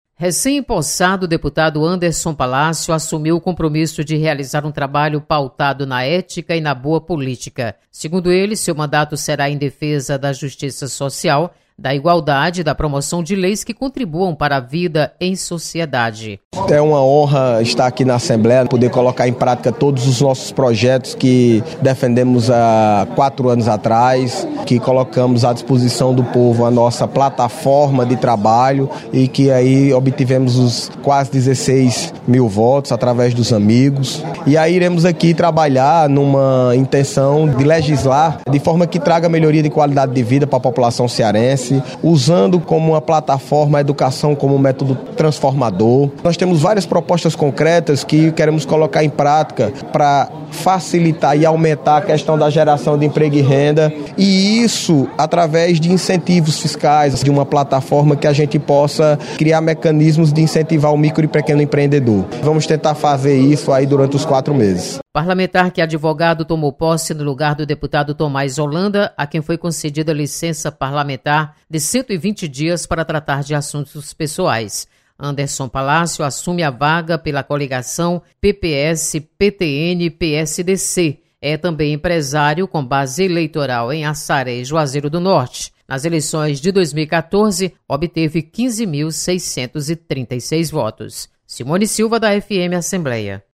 Deputado Anderson Palácio ressalta compromissos com mandato. Repórter